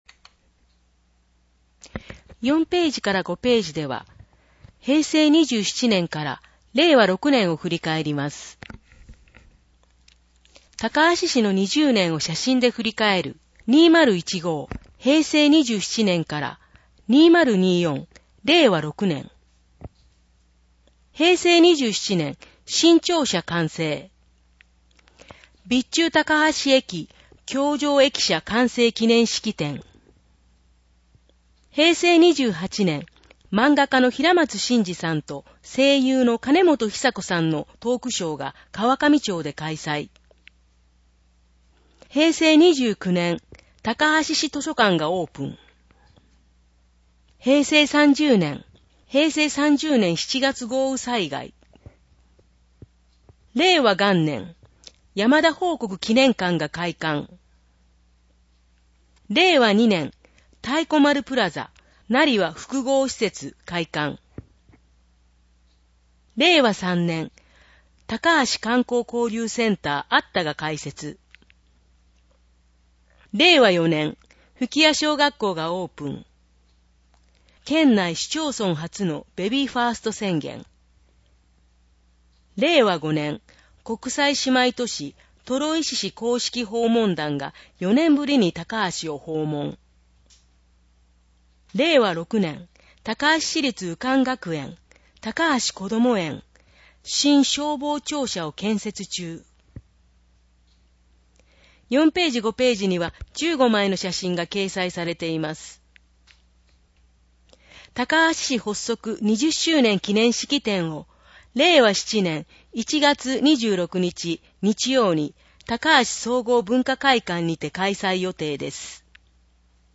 声の広報　広報たかはし10月号（240）